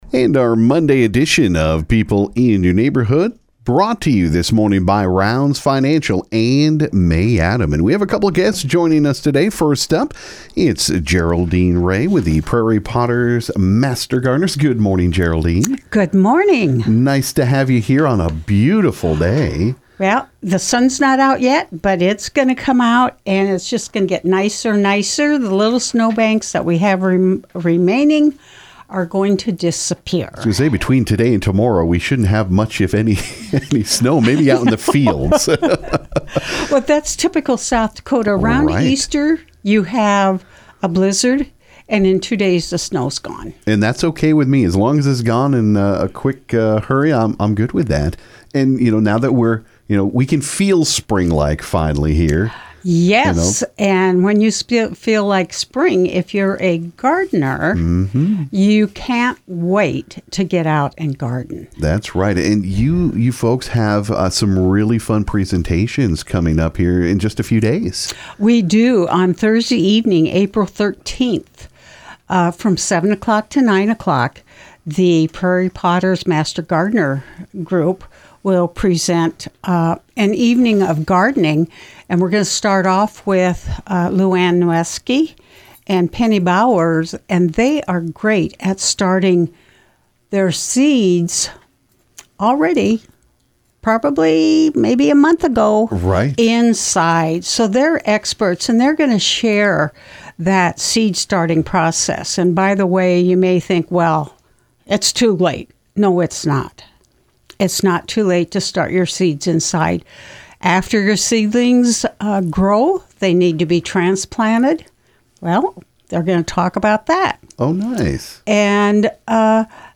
This morning on KGFX we had a couple of guests stop by for People In Your Neighborhood.